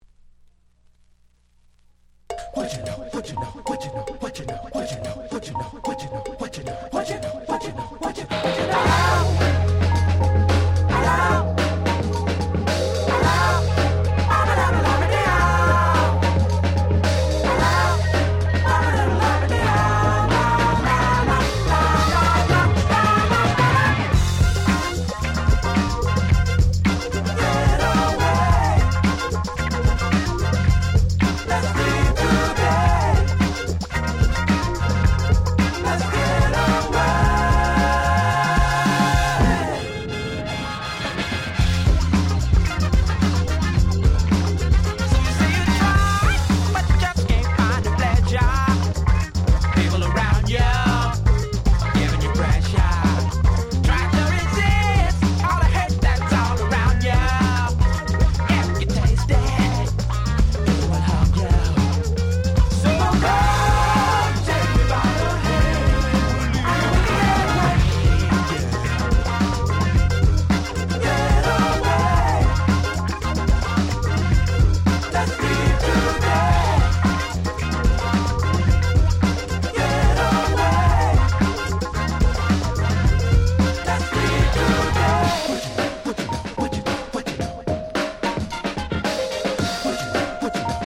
02' Very Nice Dance Classics Remixes !!